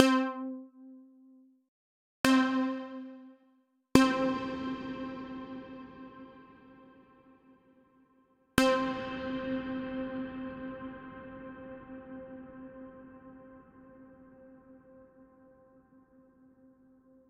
Teniendo en cuenta esta ley y lo comentado anteriormente, una reverberación es interpretada por el cerebro como un sonido continuado que va decayendo en función de las propiedades del medio.
Enlace para escuchar una reverberación.
Reverberation_effect.wav